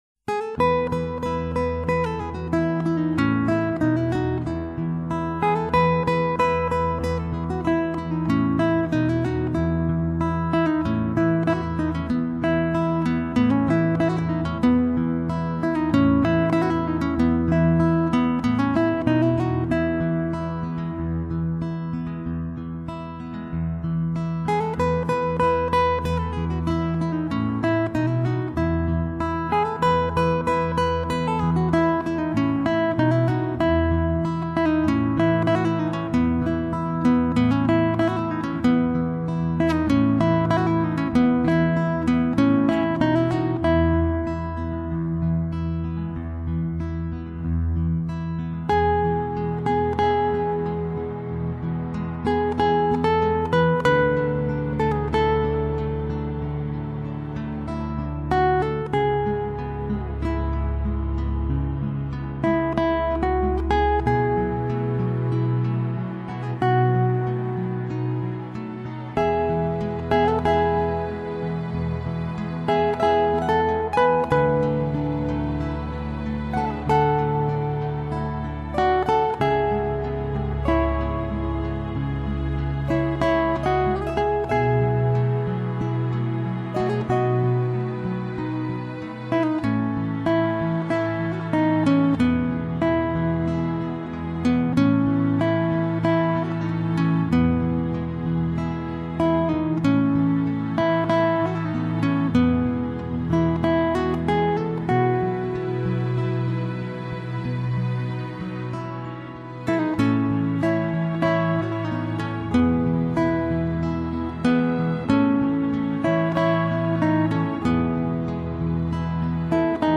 心情音乐]Happy little flowers 吉它演奏出优美的旋律，悠扬的节奏，给人的是一种境界，想必演奏者就是一位心境平和，胸怀豁达之人。